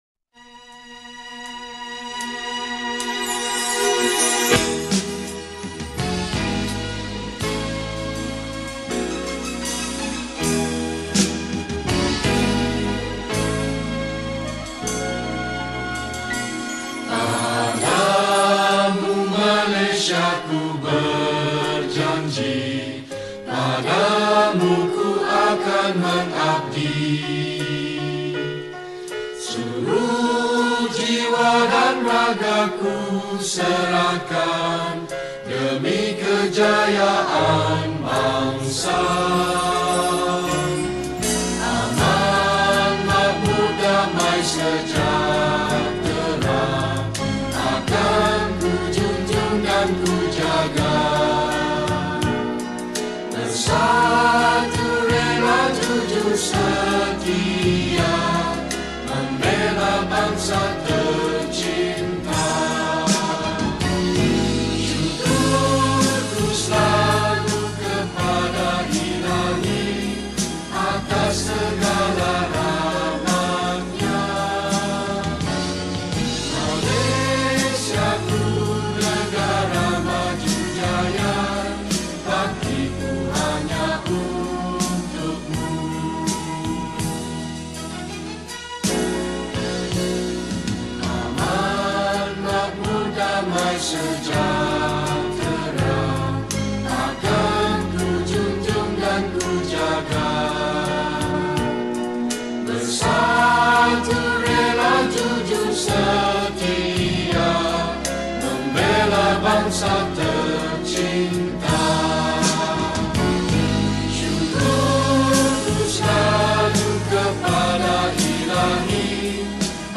Patriotic Songs
Skor Angklung